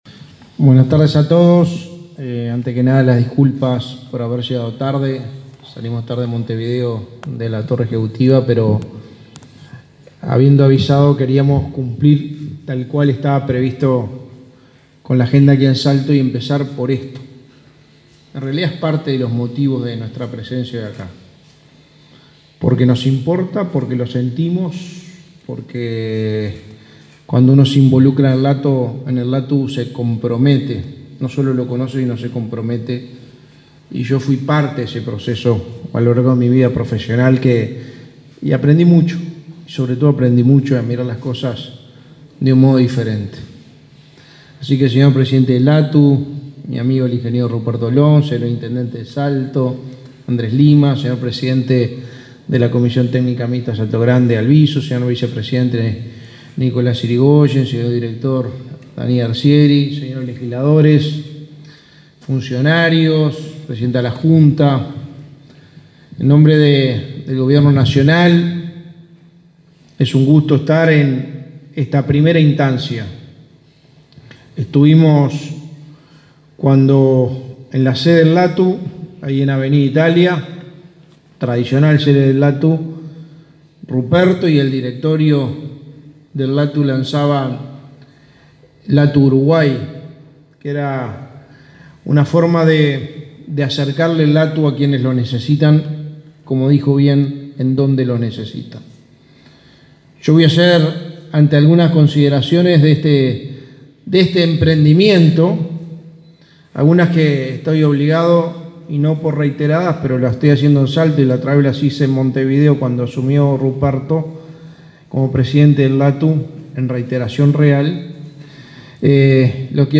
Palabras del secretario de Presidencia y del presidente del LATU
El secretario de Presidencia, Álvaro Delgado, y el presidente del LATU, Ruperto Long, participaron, este viernes 6 de agosto, de la inauguración de